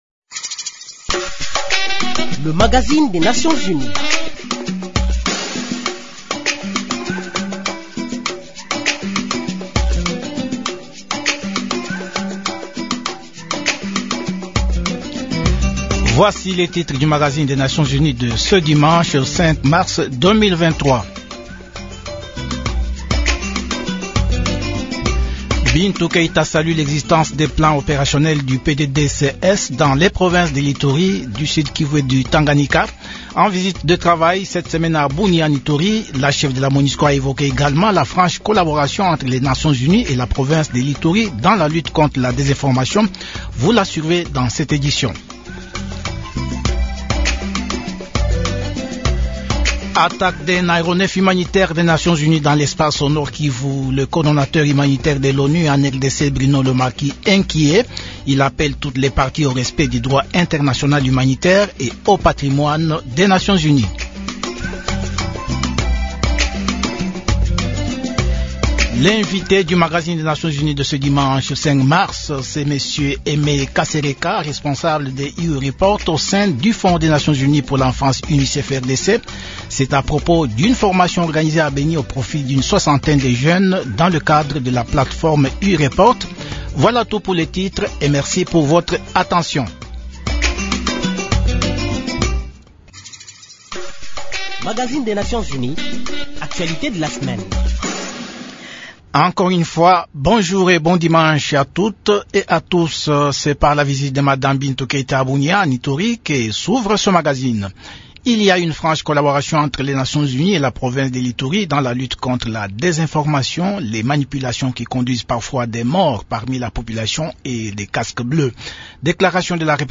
Avant de quitter la capitale congolaise où elle était de retour après l’étape de l’est, Mme ILZE BRANDS KERHIS a accordé une interview exclusive à Radio Okapi.
Nouvelles en bref *Nos nouvelles en plus bref, l’ONU-Femmes RDC a lancé jeudi 02 mars 2 023, à Kinshasa, la campagne de dissémination digitale des textes de loi en faveur des femmes.